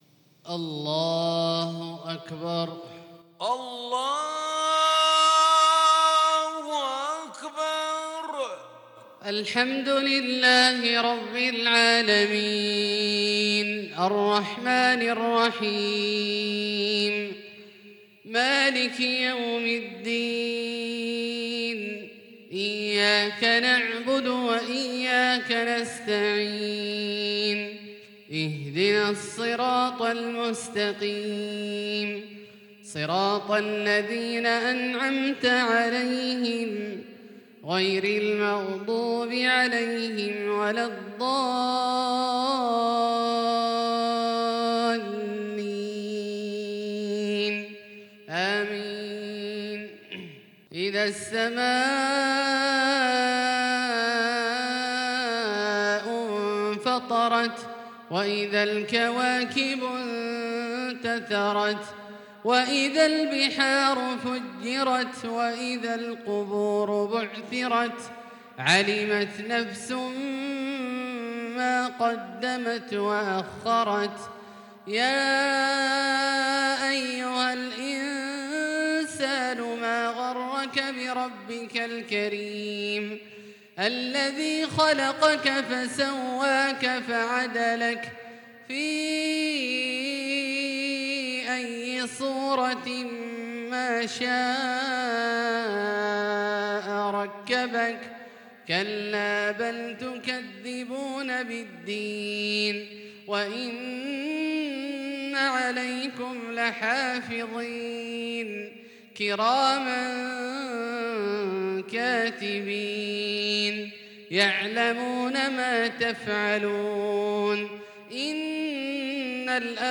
صلاة العشاء للقارئ عبدالله الجهني 23 جمادي الأول 1442 هـ
تِلَاوَات الْحَرَمَيْن .